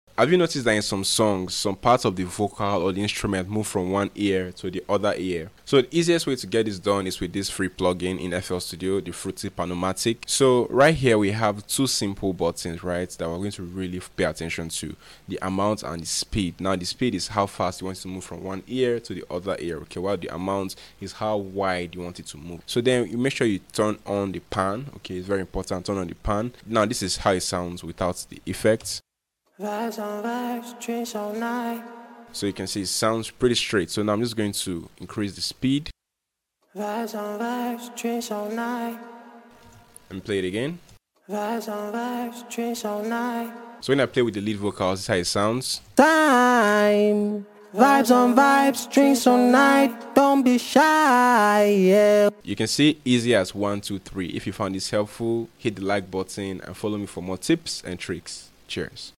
Vocal panning trick in FL sound effects free download